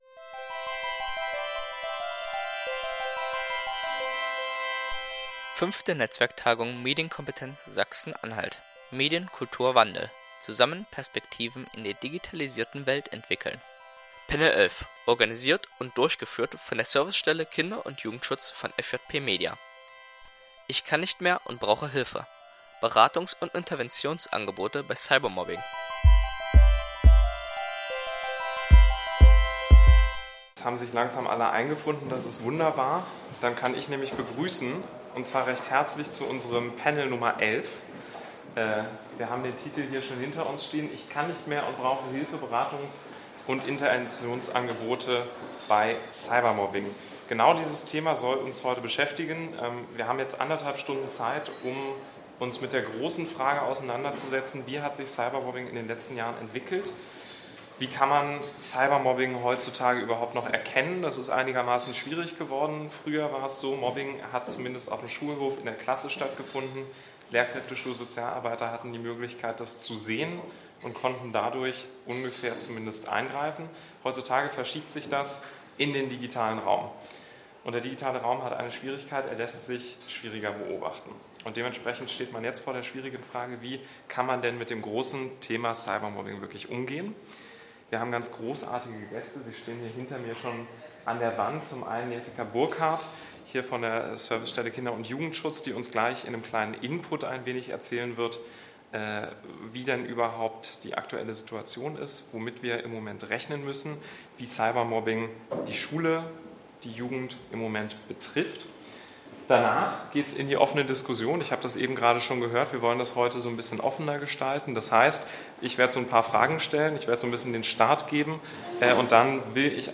Panel_11_Cybermobbing_Intervention.mp3